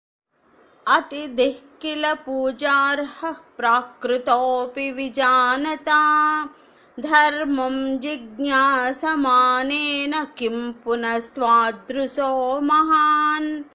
Audio Recitation